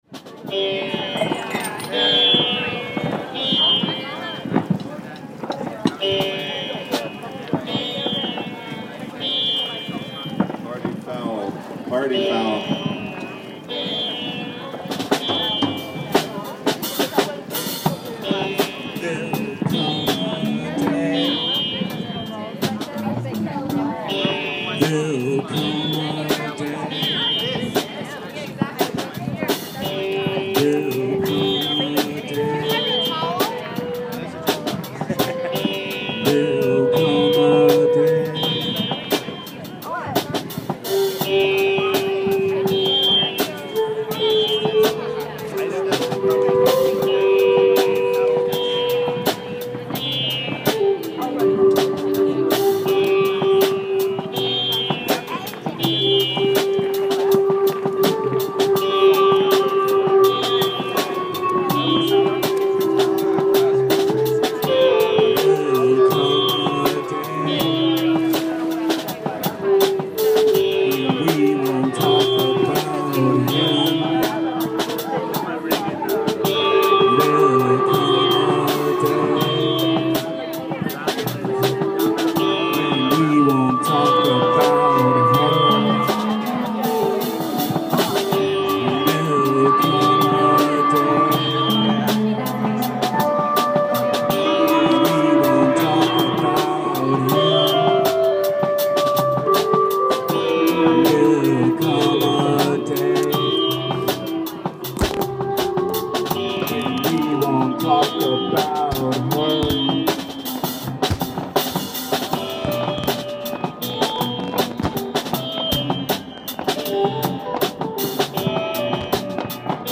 Party Foul_Come A Day vocal/sitar
synth/bass
drums
flute
ALL MUSIC IS IMPROVISED ON SITE